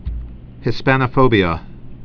(hĭ-spănə-fōbē-ə)